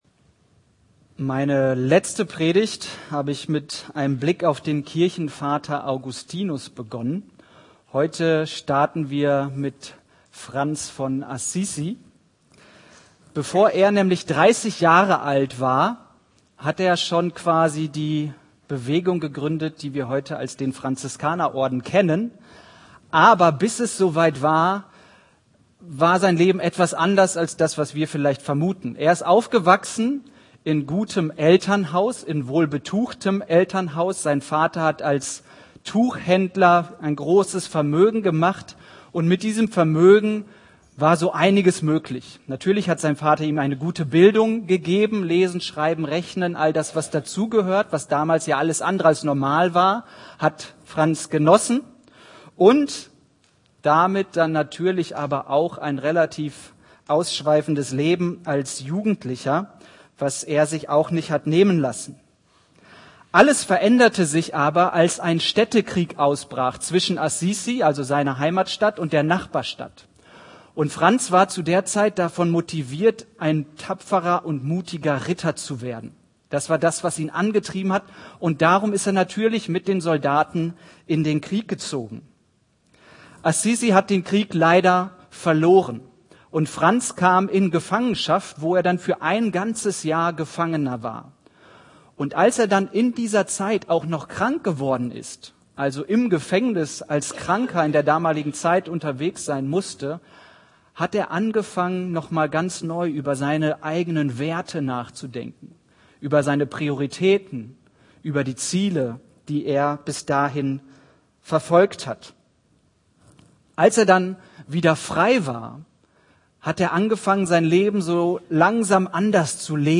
Predigt vom 23.11.2025 mit dem Thema „Umparken im Kopf“ zu Apostelgeschichte 9,1-22